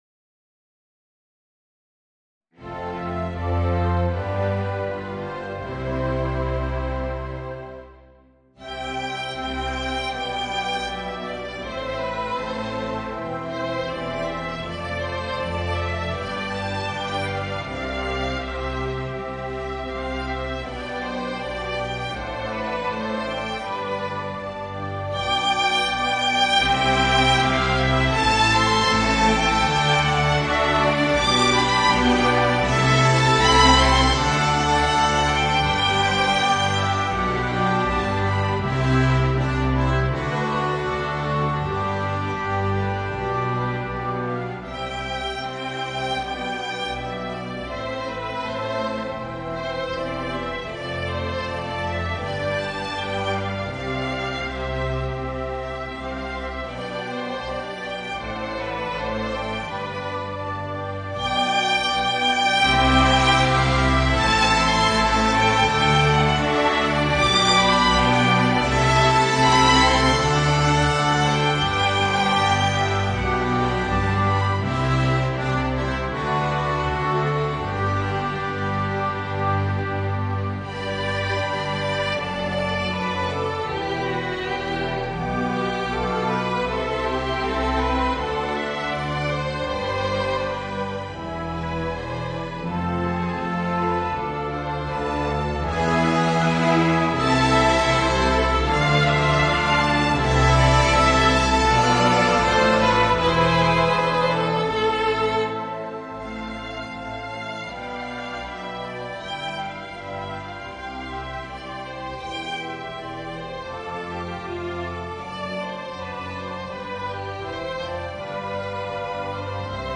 Voicing: Oboe and Orchestra